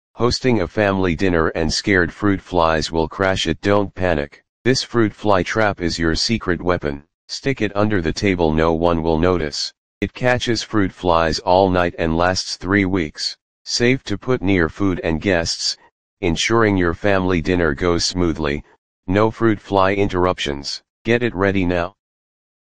No more fruit flies buzzing sound effects free download